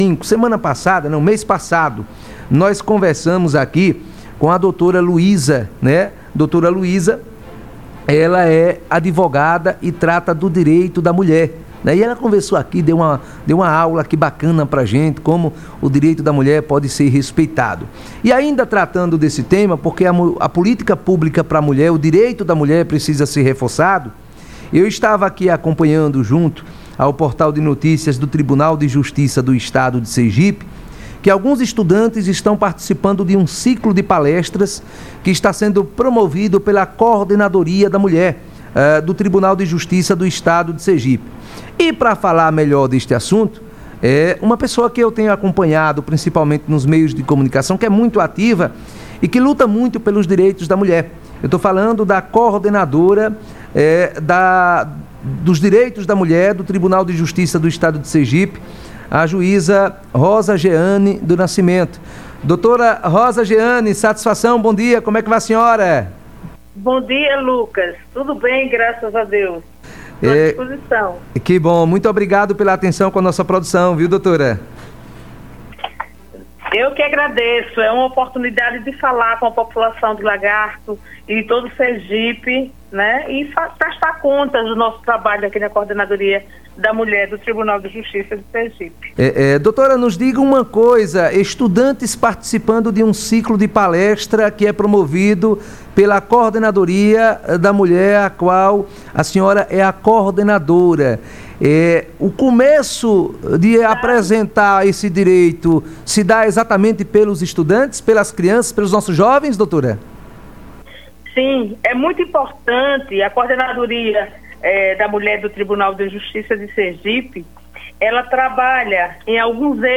Entrevista dada pela Juíza Coordenadora, Drª. Rosa Geane Nascimento Santos, a uma rádio local
entrevista_rosa_geane.mp3